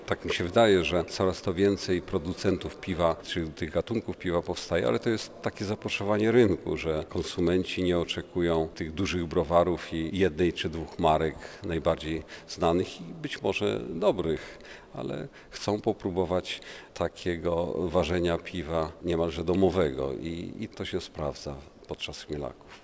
Burmistrz Jakubiec cieszy się, że co roku oferta chmielarzy i piwowarów zmienia się, dostosowując do oczekiwań smakoszy piwa, którzy poszukują nowych smaków: